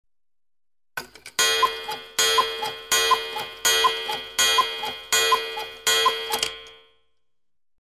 Часы с кукушкой бьют 7 раз
Тут вы можете прослушать онлайн и скачать бесплатно аудио запись из категории «Часы, бой часов».